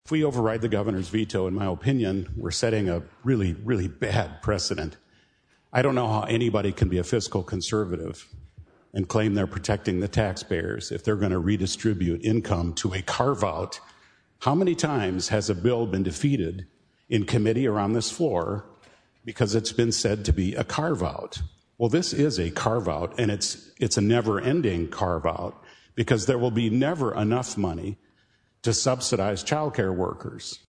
Rep. John Hughes, R-Sioux Falls, opposed the override.